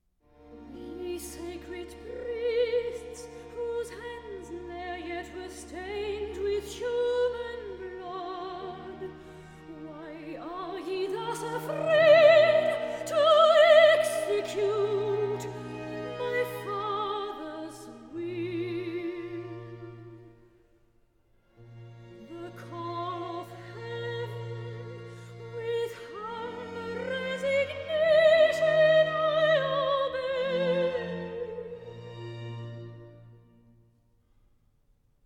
Accompagnato